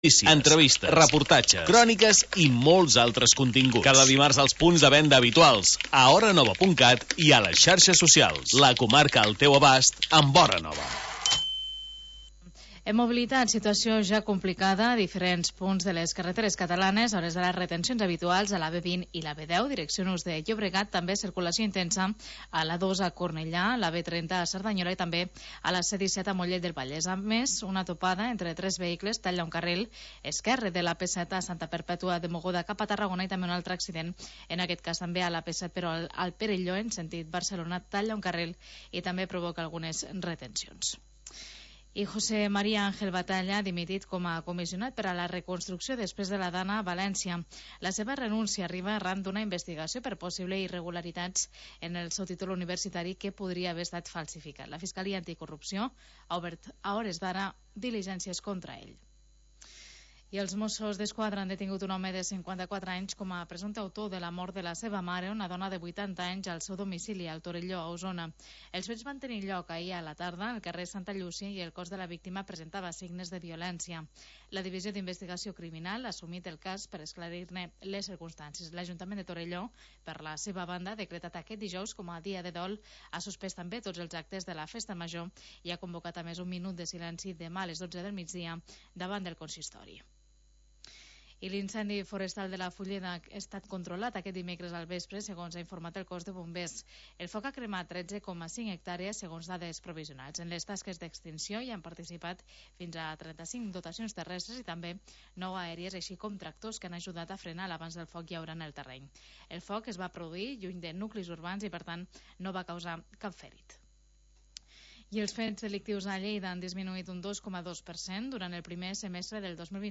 Magazín territorial d'estiu